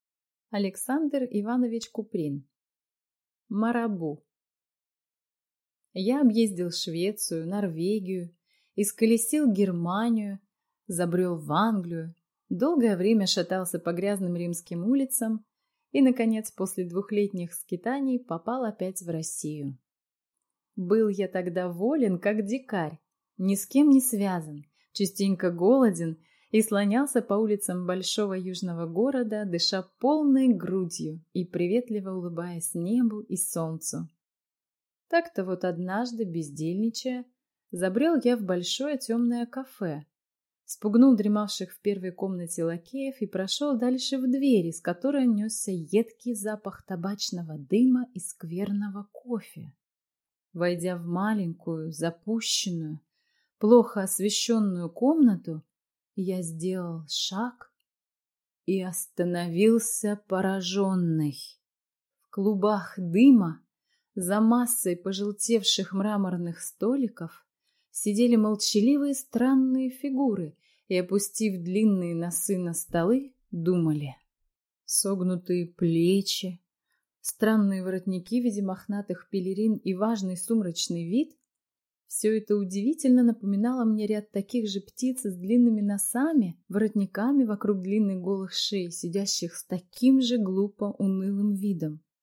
Аудиокнига Марабу | Библиотека аудиокниг